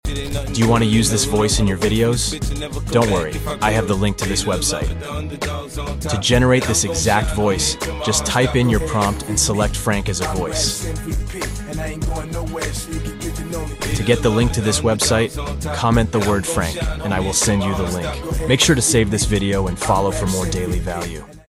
Best sounding text to speech sound effects free download